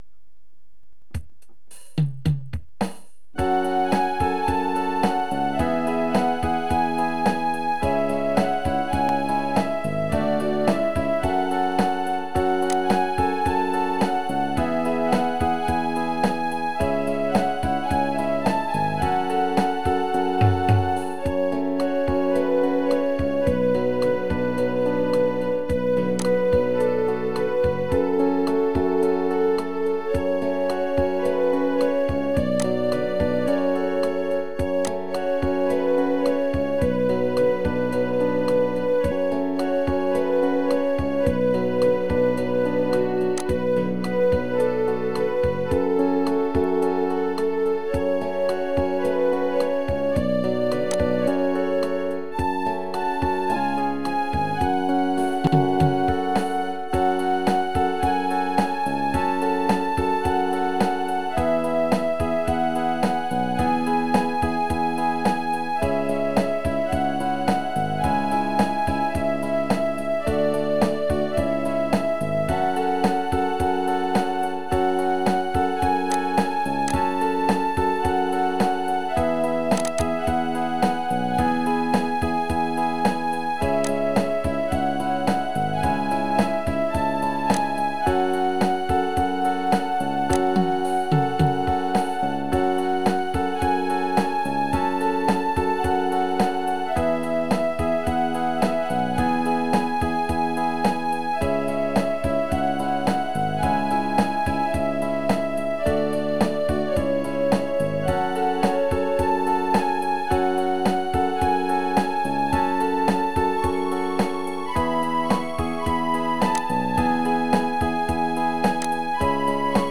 נגינה באורגן. האיכות לא משהו, אבל זה מה יש.
חבל שהקצב בפיזמון בהתחלה ובבית הוא לא אותו דבר.